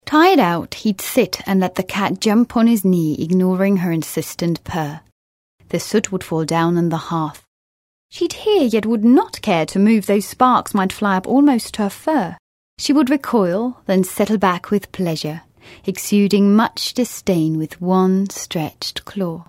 Junge, freundliche, warme, fröhliche englische Stimme für Voice Overs, Synchronisation und Werbung.
Sprechprobe: Industrie (Muttersprache):
A warm, friendly, young and happy sounding voice.